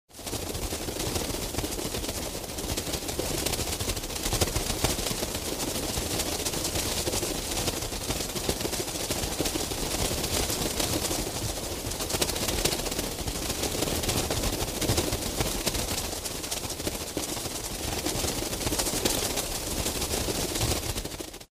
Звуки летучей мыши
Стая летучих мышей машет крыльями